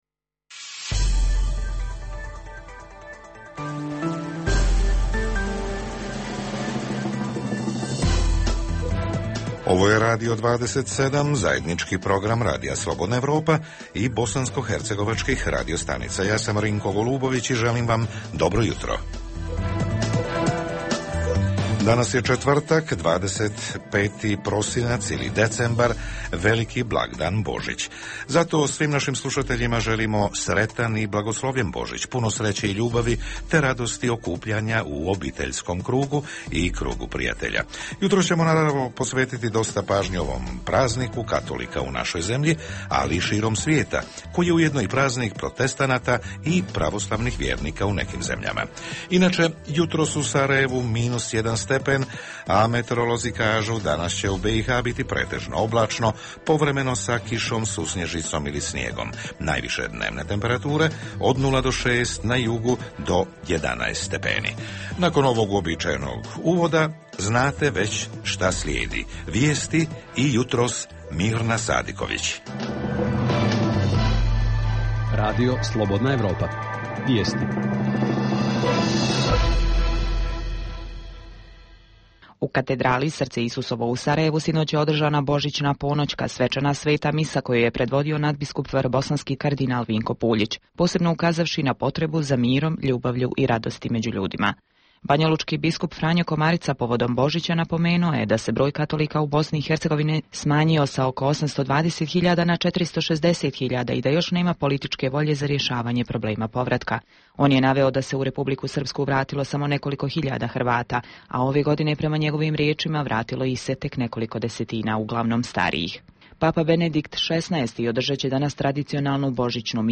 Jutarnji program za BiH koji se emituje uživo. Danas govorimo o praznicima, porodičnim okupljanjima nekad i sad, te šta blagdani znače obitelji.
Redovni sadržaji jutarnjeg programa za BiH su i vijesti i muzika.